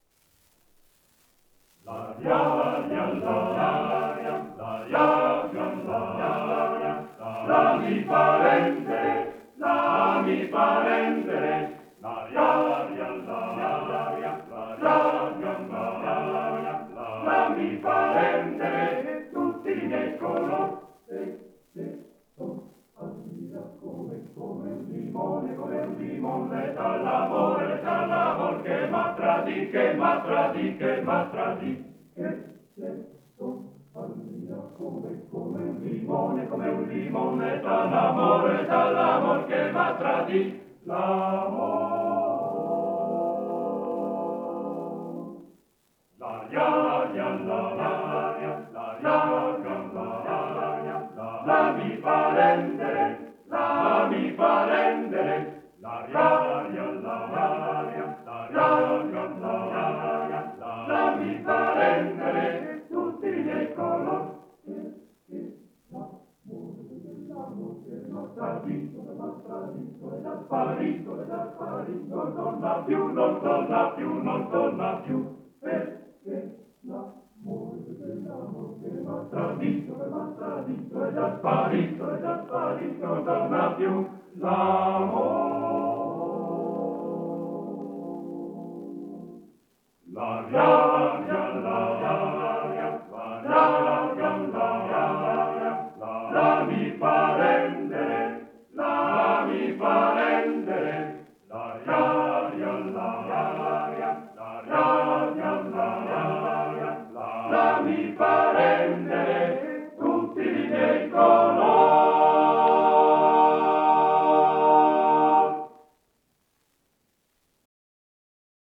Arrangiatore: Pigarelli, Luigi
Esecutore: Coro della SAT